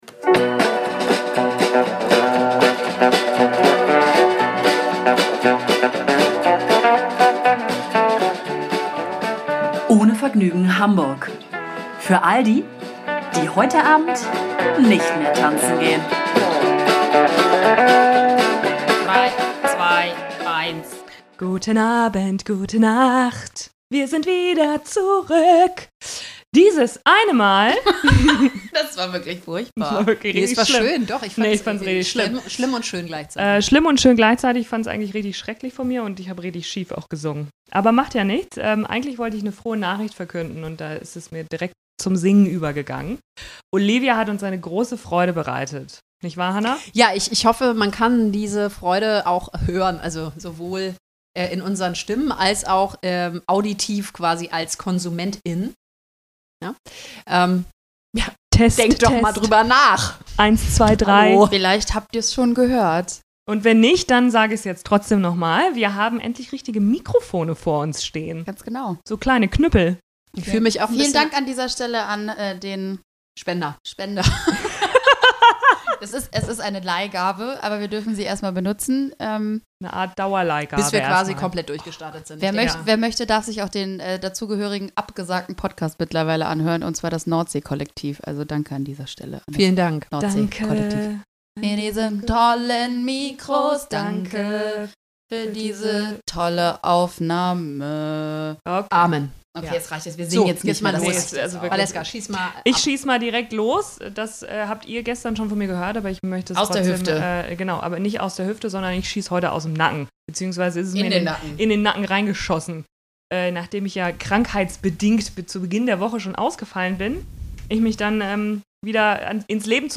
Wir starten die Folge mit einem musikalischen Meisterwerk – einer schiefen Gesangseinlage, die eure Ohren betteln lässt, dass wir doch bitte wieder sprechen sollen.